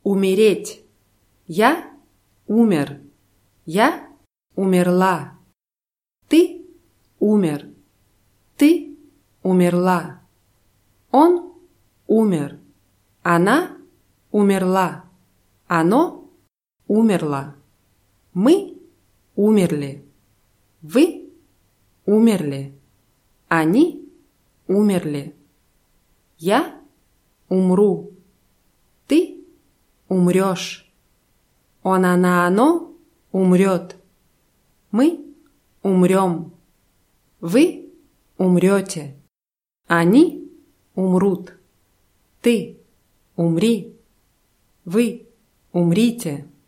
умереть [umʲirʲétʲ]